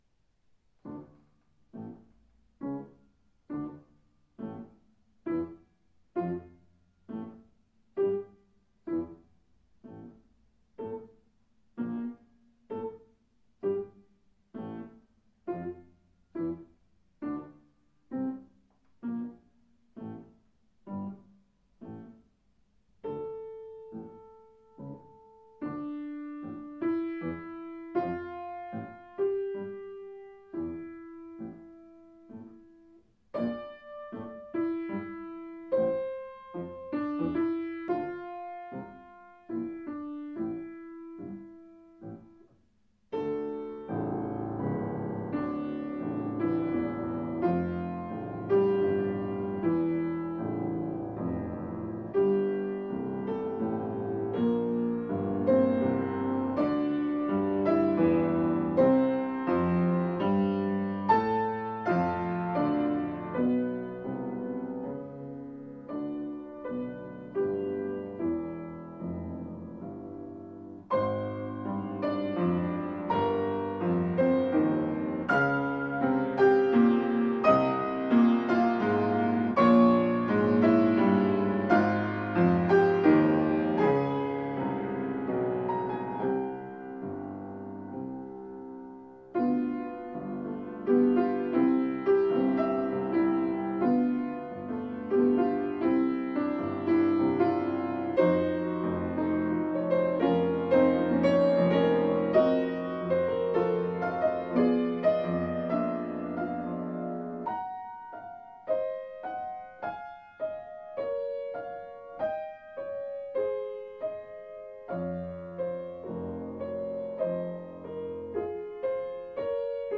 piano transcription